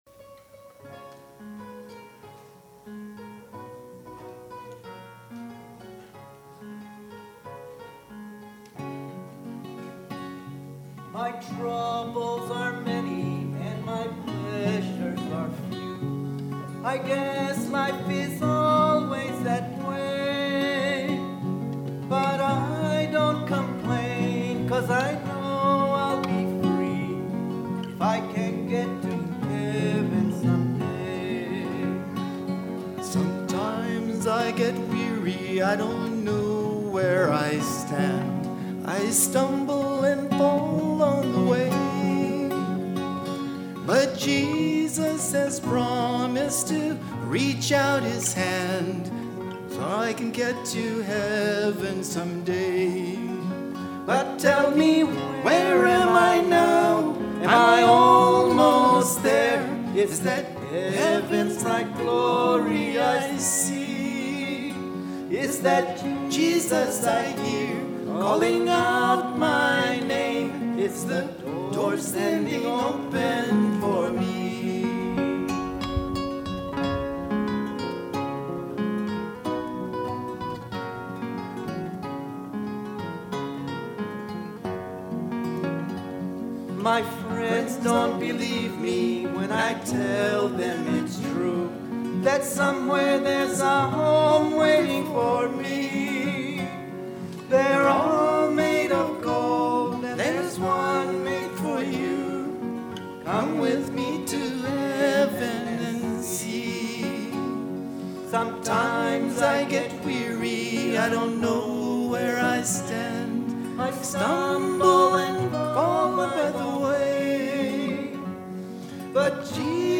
2016 Sermons